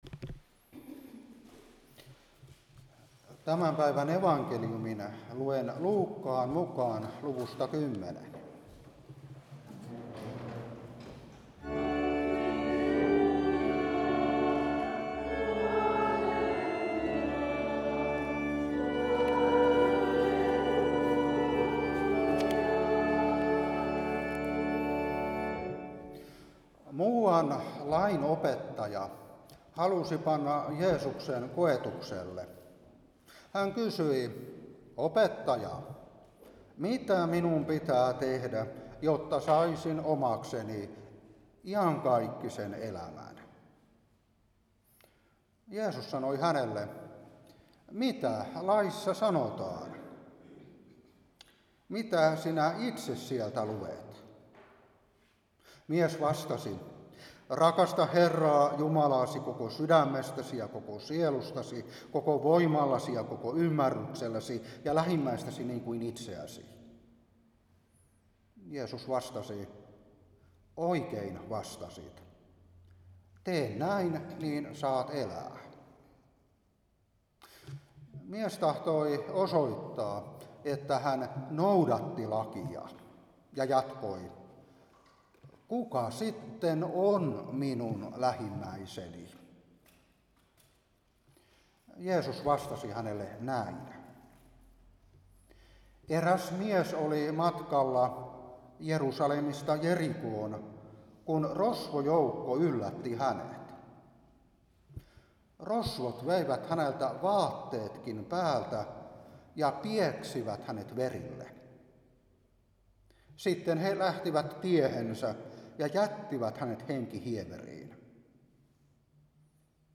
Saarna 2025-9.